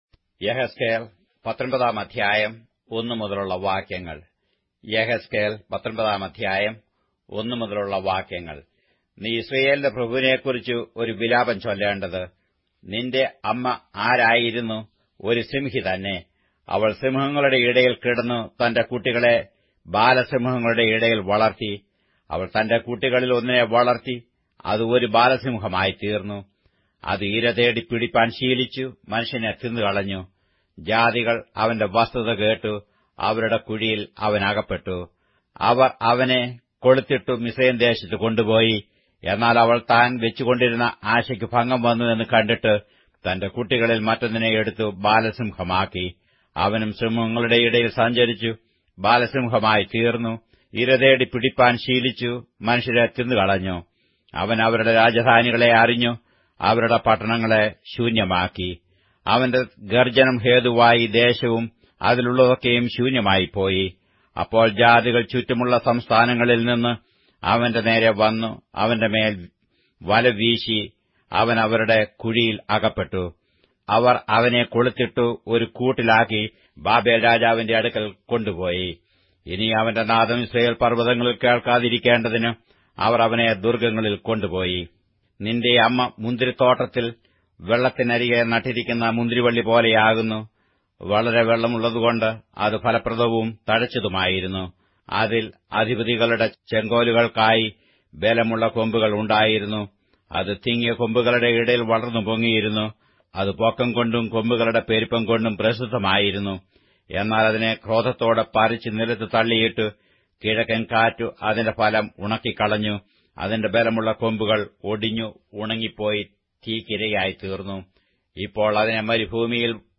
Malayalam Audio Bible - Ezekiel 6 in Nlt bible version